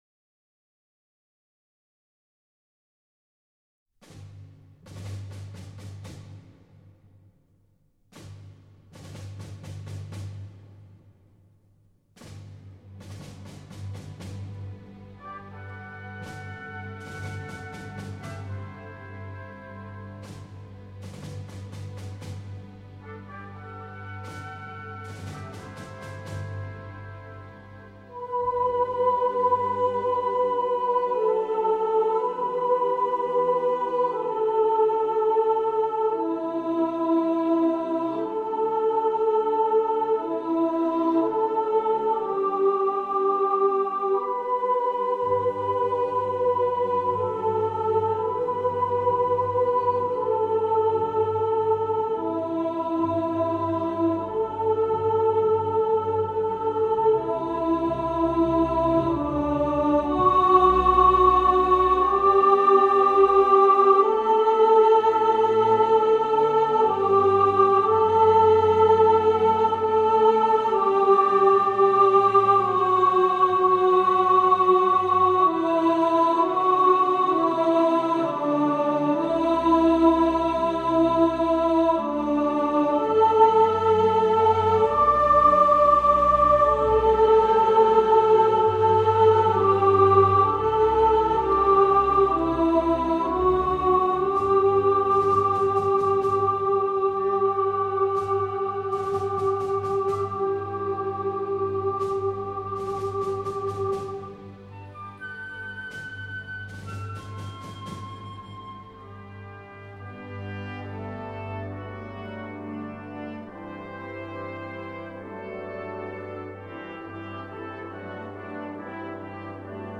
Hymn To The Fallen – Soprano | Ipswich Hospital Community Choir